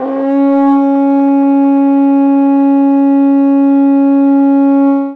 Index of /90_sSampleCDs/Best Service ProSamples vol.52 - World Instruments 2 [AKAI] 1CD/Partition C/TENOR HORN